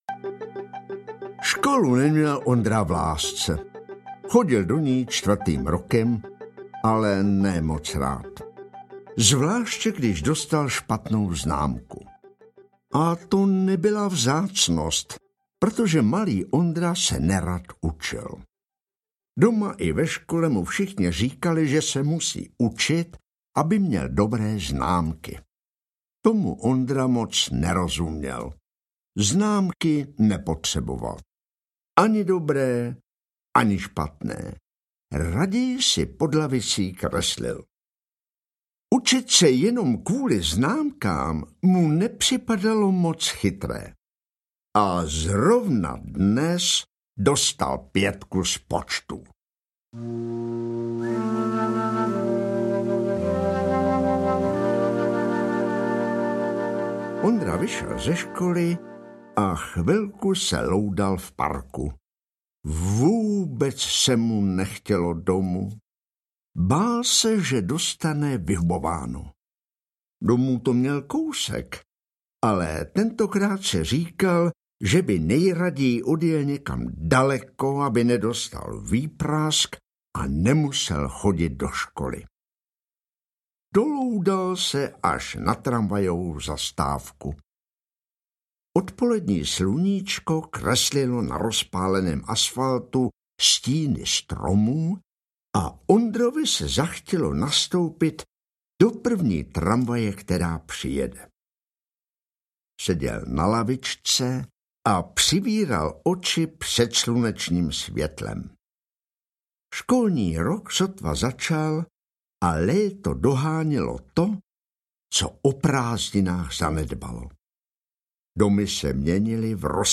Ondra a klaun audiokniha
Ukázka z knihy